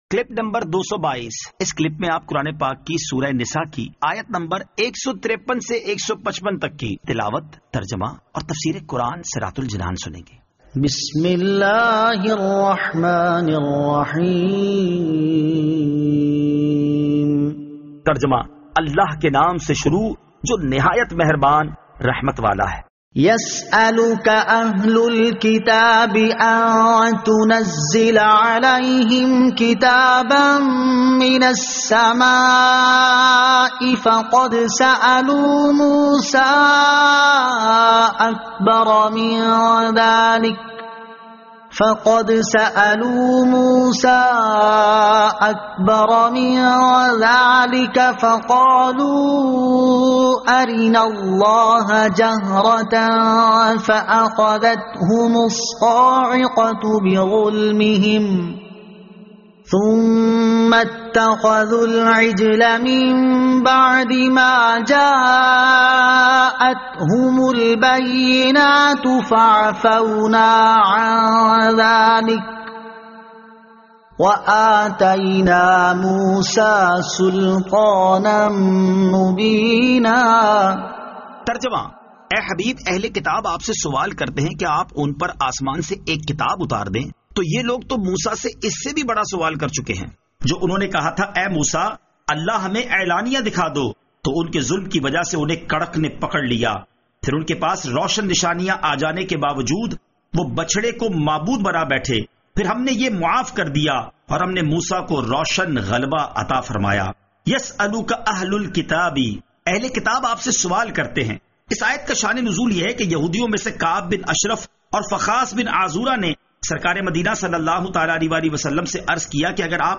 Surah An-Nisa Ayat 153 To 155 Tilawat , Tarjama , Tafseer